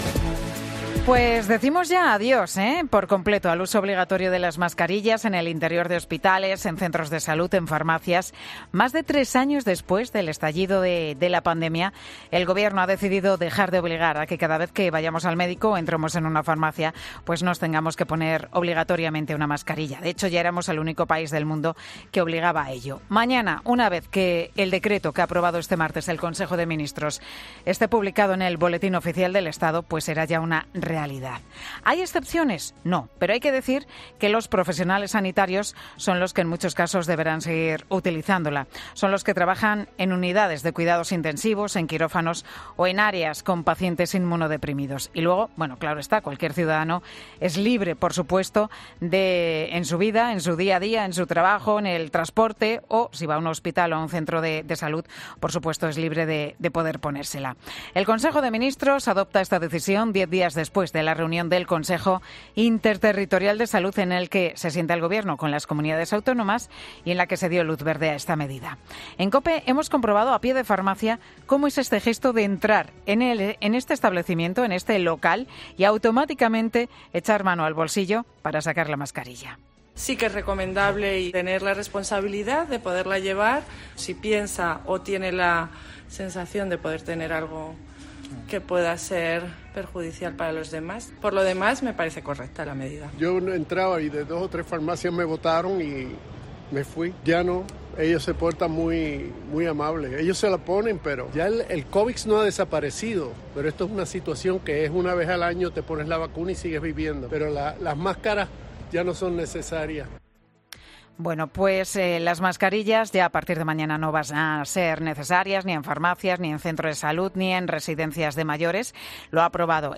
Preguntamos a los oyentes en COPE qué les parece la retirada de las mascarillas